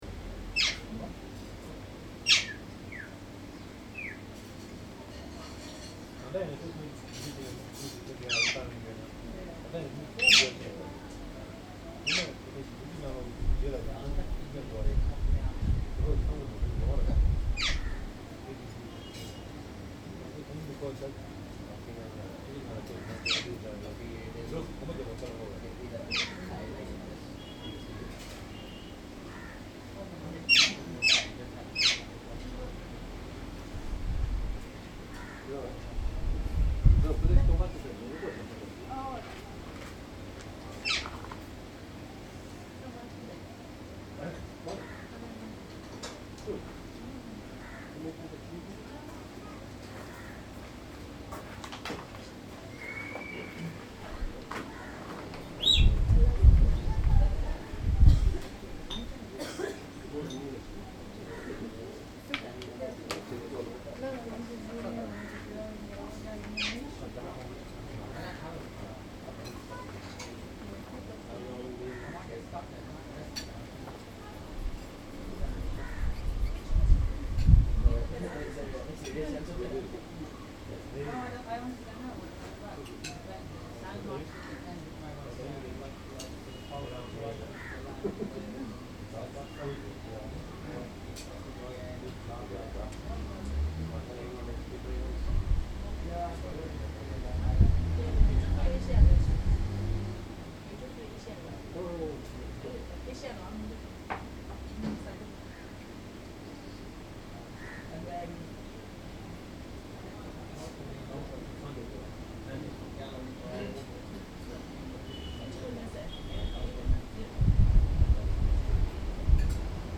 Prête moi tes yeux : perroquet
02/22/2014 02:00pm On the little tibetan restaurant of Kochi, there is a parrot.
The customers laught, but sometimes also they are surprised and scared, it is fun to look at them.